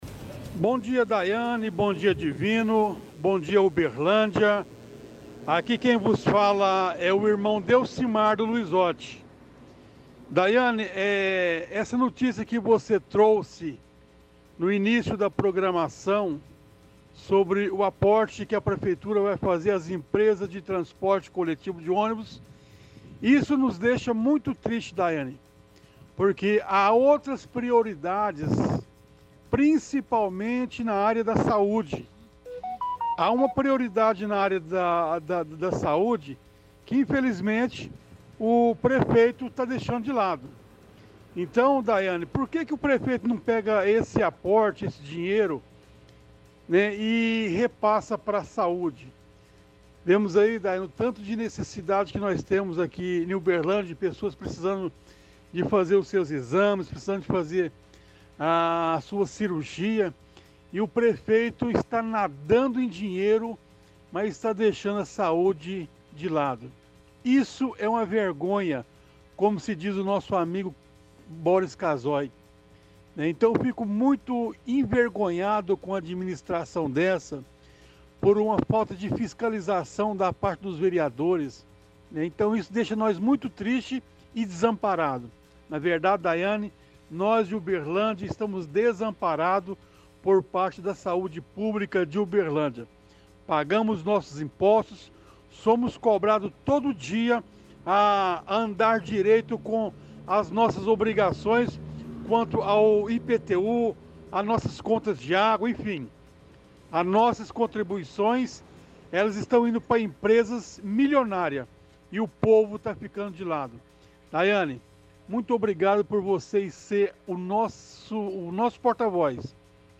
– Ouvinte reclama de aporte para as empresas de transporte público, quando o dinheiro deveria ser na verdade investido na saúde.
– Outra ouvinte reclama de o prefeito dar dinheiro para empresas de ônibus enquanto os alunos estão sem transporte escolar.
– Ouvinte discorda de aporte dizendo que de qualquer forma o dinheiro vai sair do bolso dos contribuintes.